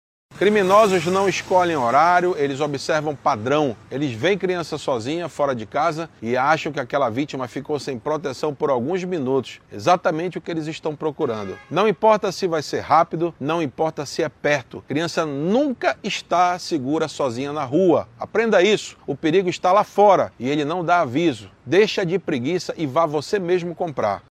SONORA-02-DELEGADO-1-1.mp3